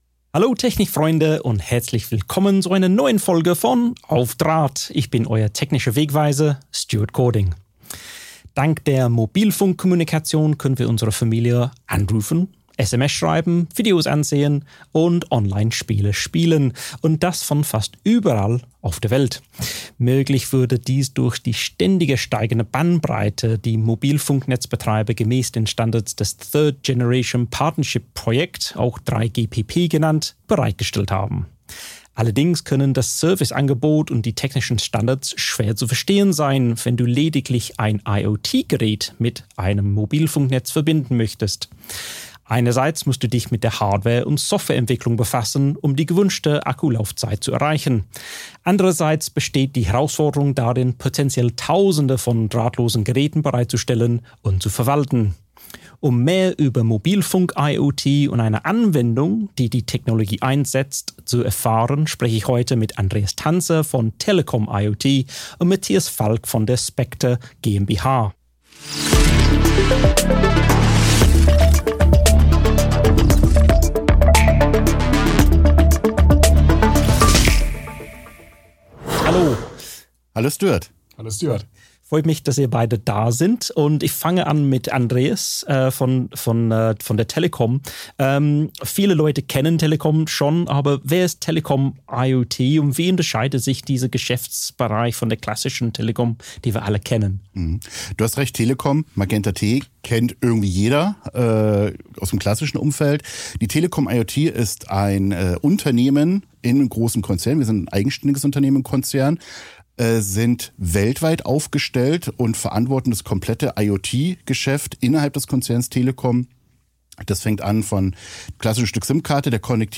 Wie diese Herausforderungen gemeistert werden können, zeigt die Diskussion